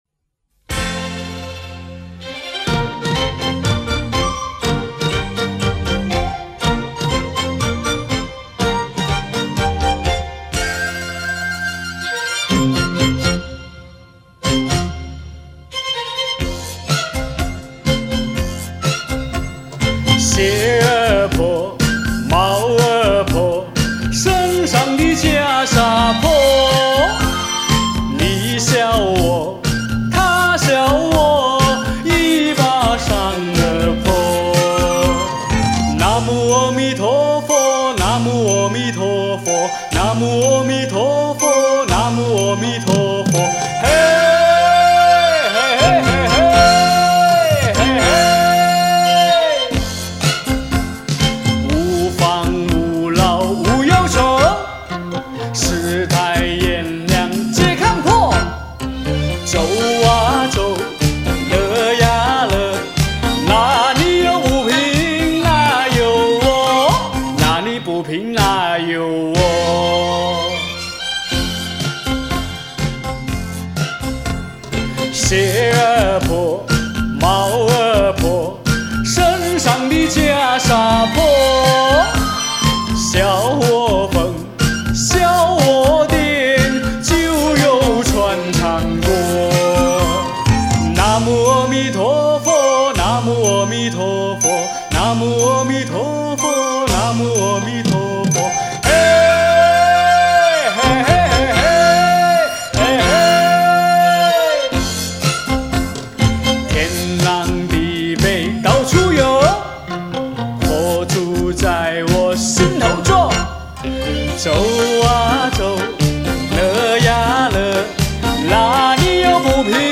唱首让心情轻松的歌曲。
原伴奏还有一节的，觉得重复太多了，就剪了后面的一段了。